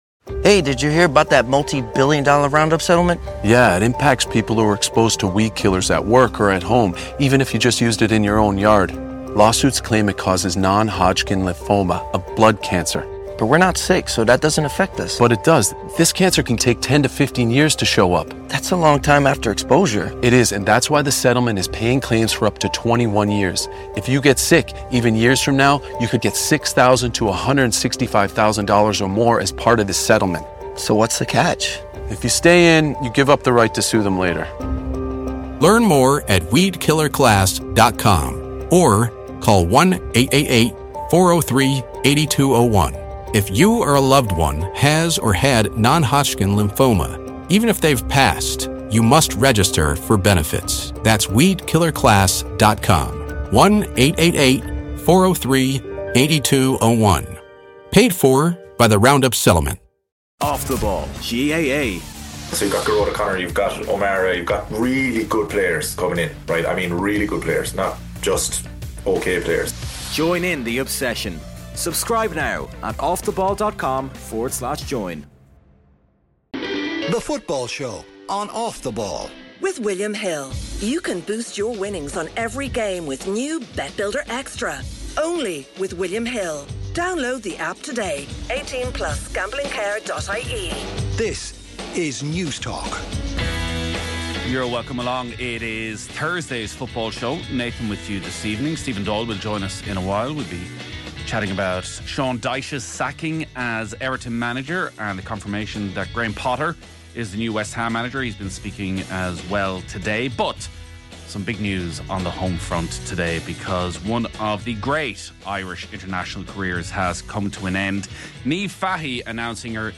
for a relaxed chat on an assortment of football topics